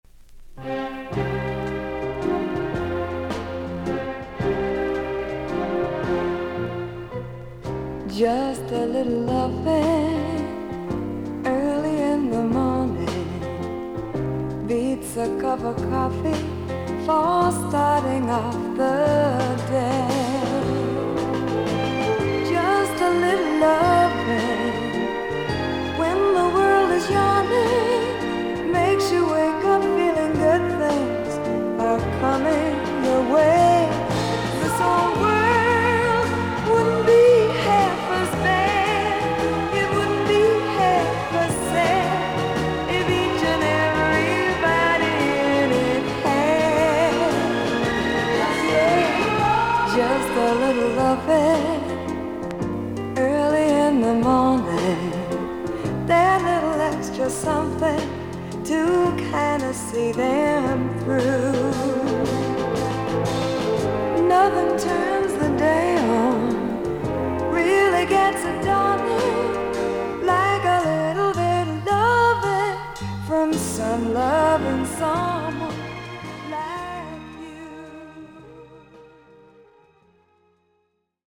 少々軽いパチノイズの箇所あり。クリアな音です。
女性シンガー。ソフィスティケイトされたR&B/ソフト・ロック・テイストのサウンドが心地よい名アルバム。